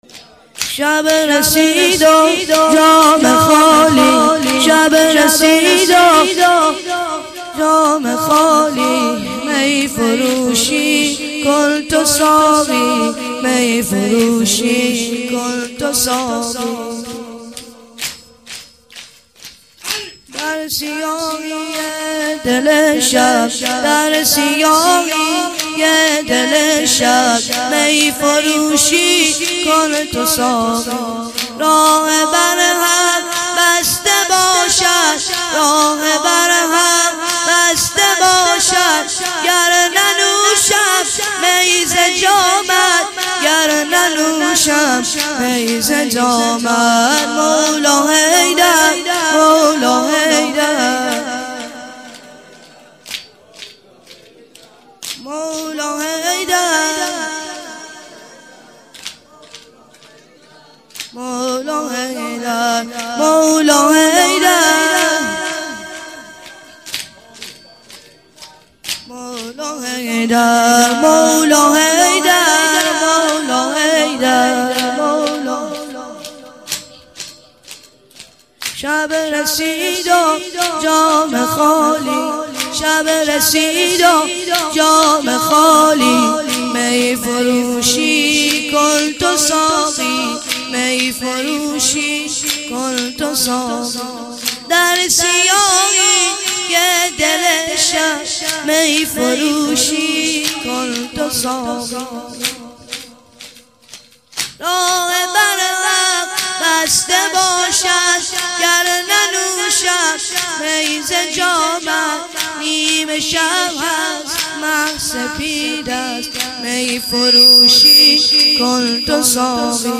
چهار ضرب - شب رسید و جام خالی